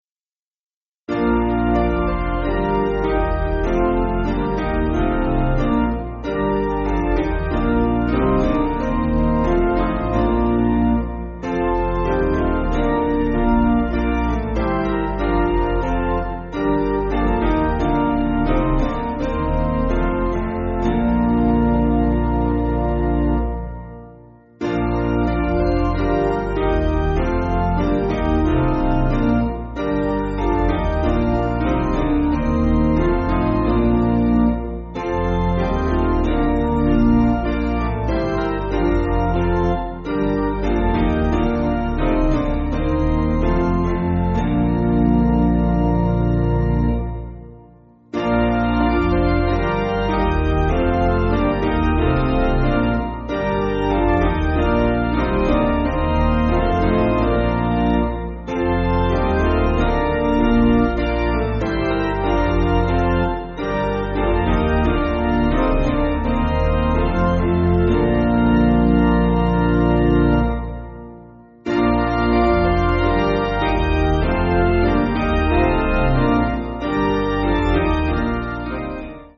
Basic Piano & Organ
(CM)   4/Bb